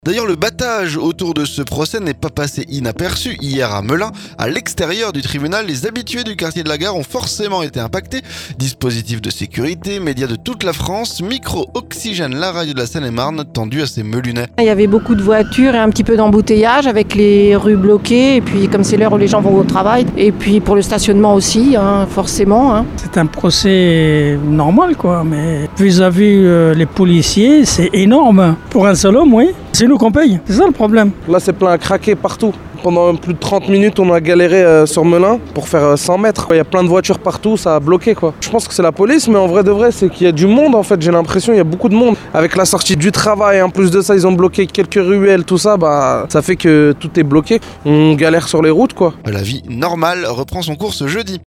Micro Oxygène la radio de la Seine-et-Marne tendu à ces Melunais.